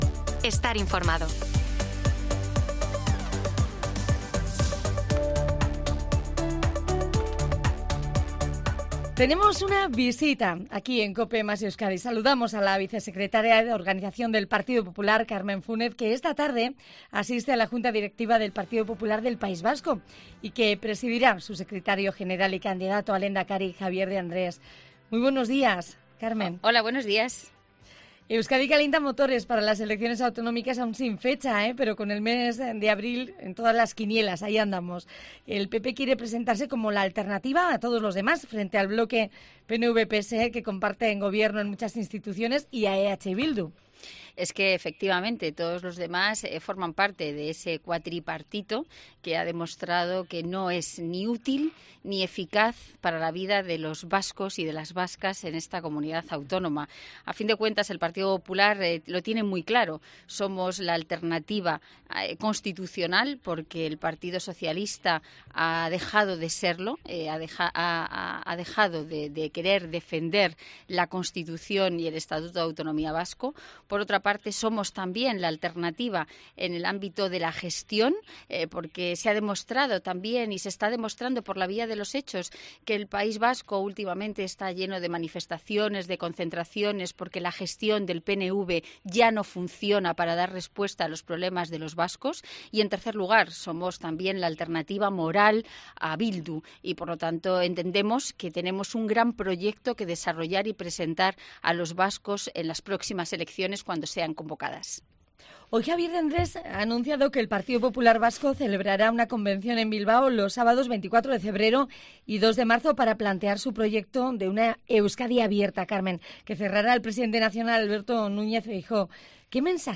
Entrevista a Carmen Fúnez, vicesecretaria de organización del PP